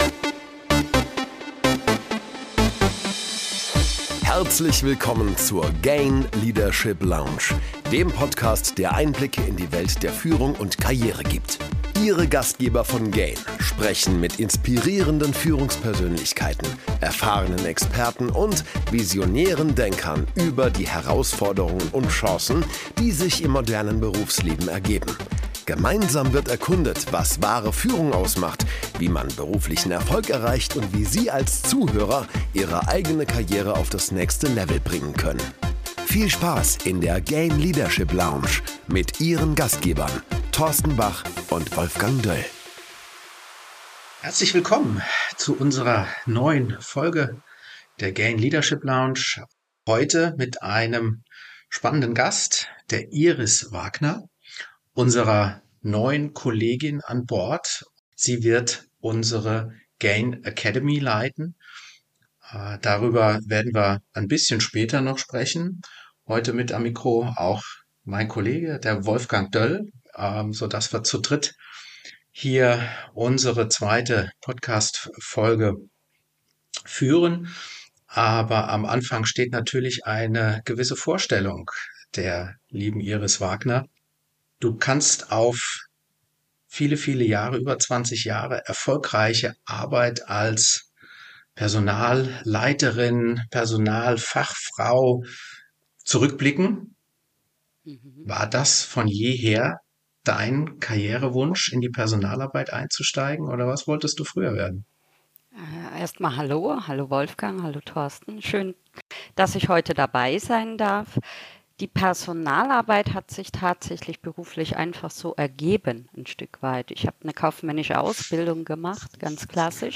Interview, Leadership, Academy, GAIN